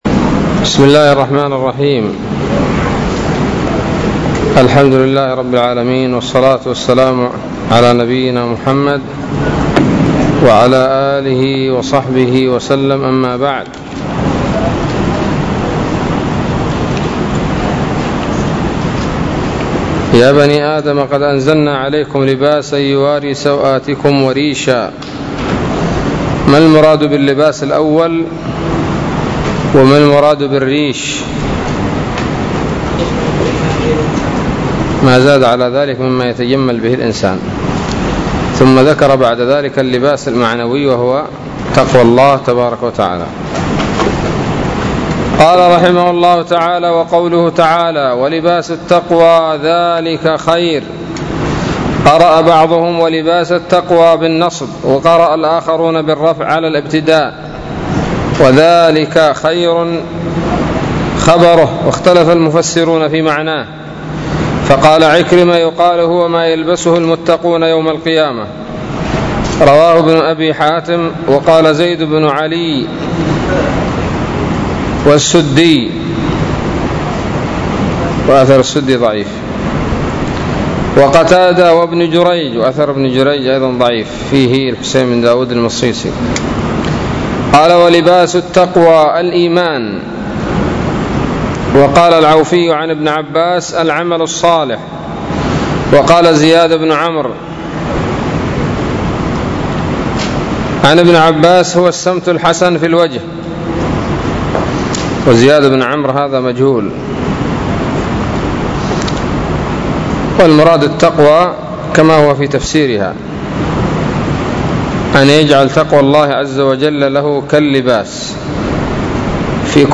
007 سورة الأعراف الدروس العلمية تفسير ابن كثير دروس التفسير
الدرس التاسع من سورة الأعراف من تفسير ابن كثير رحمه الله تعالى